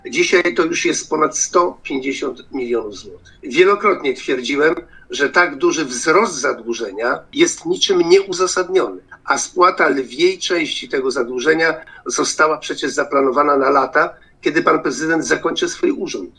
– Zadłużenie Ełku cały czas rośnie – mówił radny Krzysztof Wiloch podczas dyskusji nad raportem.